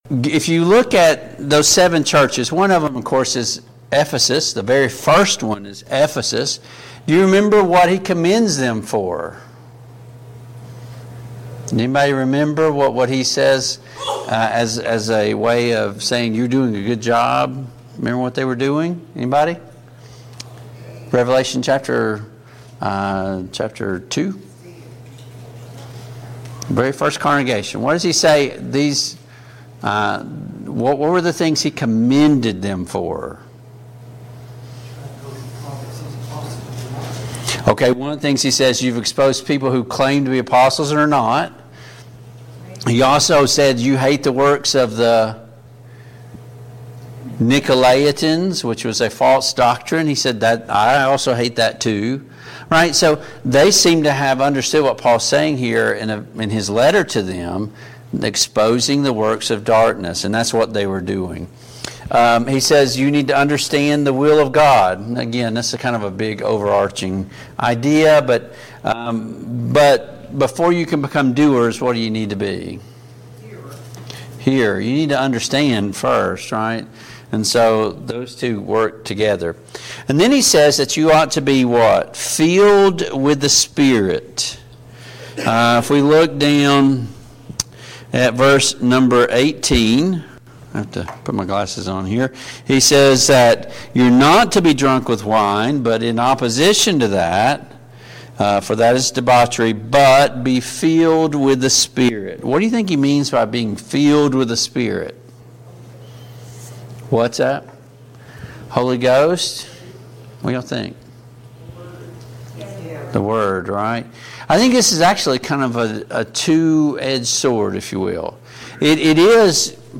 Passage: Ephesians 5:1-21 Service Type: Mid-Week Bible Study Download Files Notes « 24.